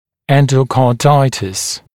[ˌendəukɑː’daɪtɪs][ˌэндоука:’дайтис]эндокардит